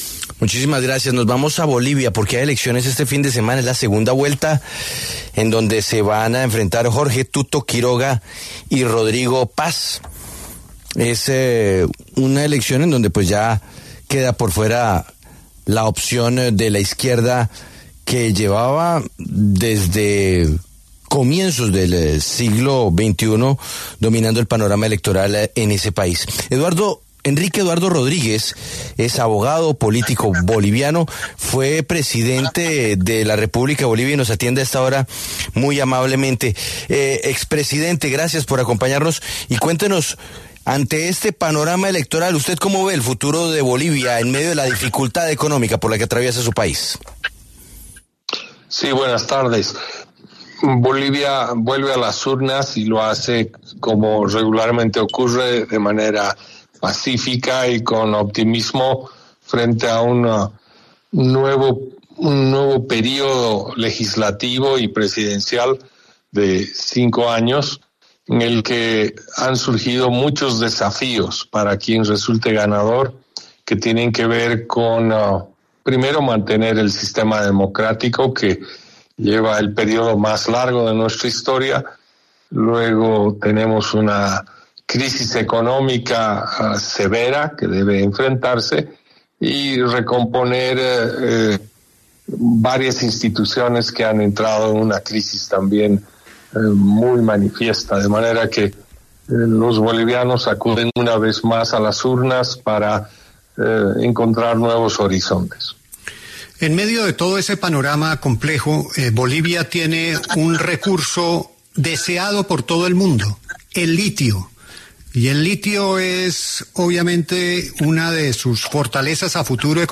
Enrique Eduardo Rodríguez Veltzé, expresidente de la República de Bolivia, pasó por los micrófonos de La W para hablar sobre las elecciones que se vienen para el país.